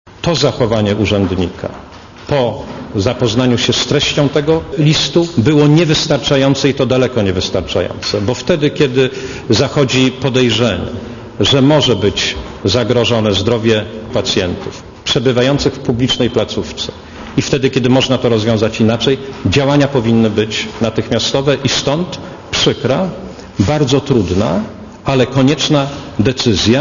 Minister na konferencji prasowej powiedział, że urzędniczka jego resortu zareagowała "wysoce niewystarczająco" w sytuacji zagrożenia zdrowia i życia chorych w szpitalu kutnowskim.